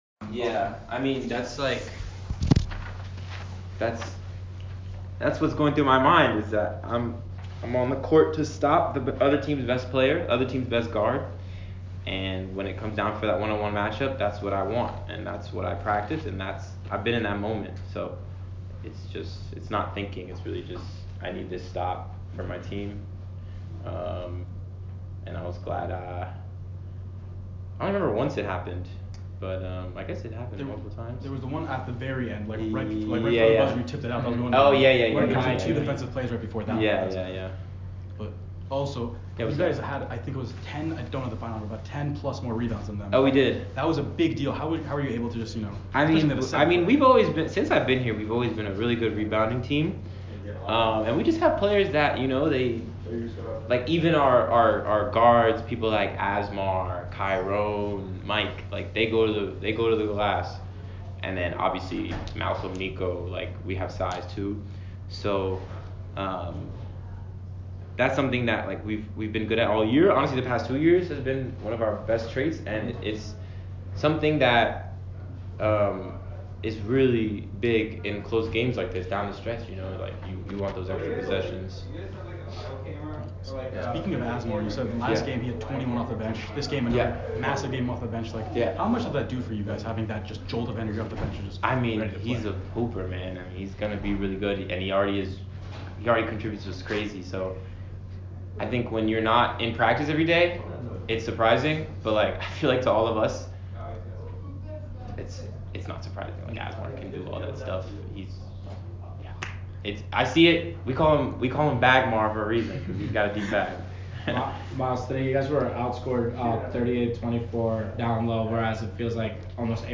Bucknell Postgame Interview